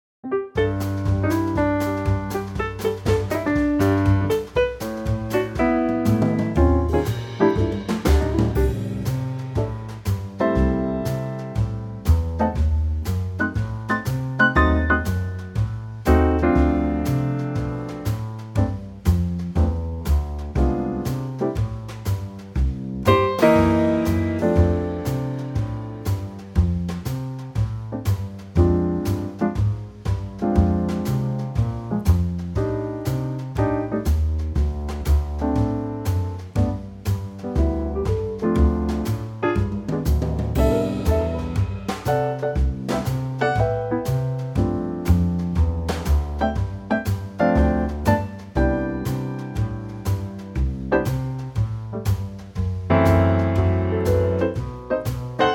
Unique Backing Tracks
key - C - vocal range - A to A
Nice 2025 Trio arrangement of this popular classic standard.